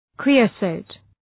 Shkrimi fonetik {‘kri:ə,səʋt}